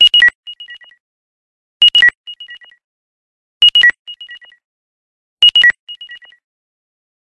nokia-lumia-alert-find-phone_24521.mp3